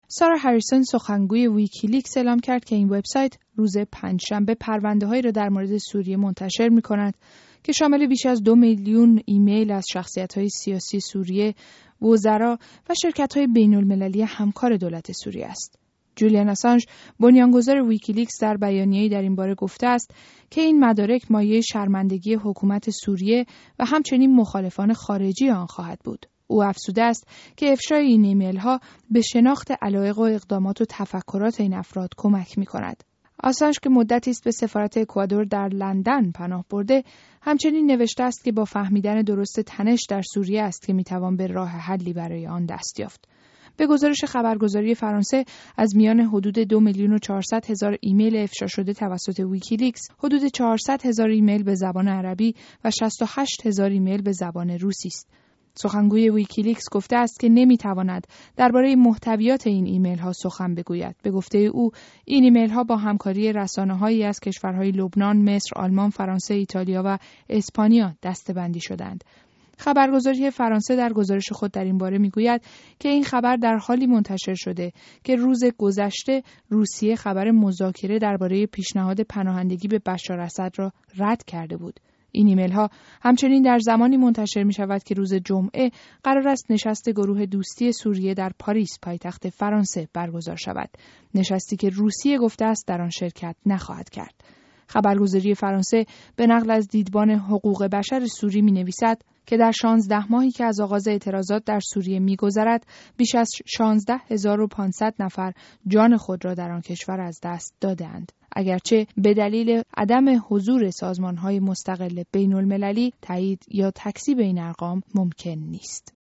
گزارش رادیویی؛ انتشار «۲.۴ میلیون ایمیل» مرتبط با سوریه به دست ویکی‌لیکس